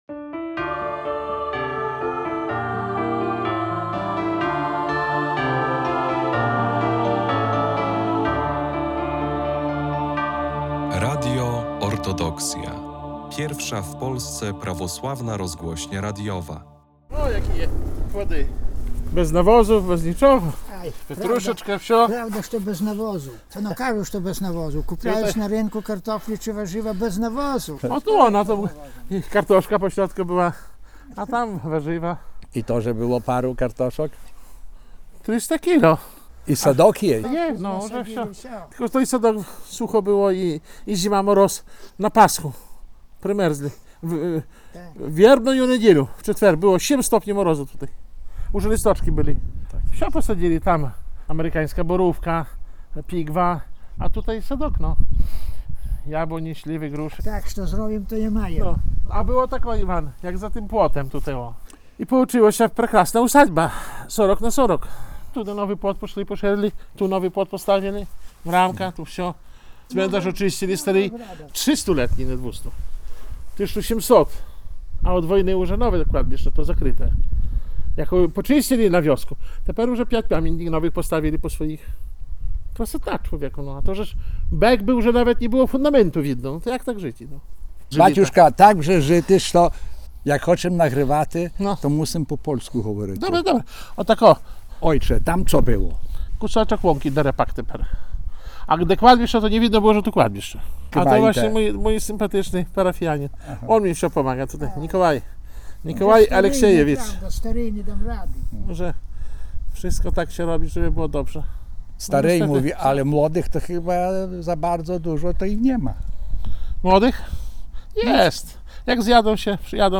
A kiedyś to było… rozmowy o życiu i Cerkwi – to cykl audycji radiowych z prawosławnymi duchownymi, którzy tworzyli historię Polskiego Autokefalicznego Kościoła Prawosławnego. W każdym odcinku programu zaproszeni goście opowiadają o swoich doświadczeniach związanych z posługą duszpasterską na przestrzeni ostatnich kilkudziesięciu lat.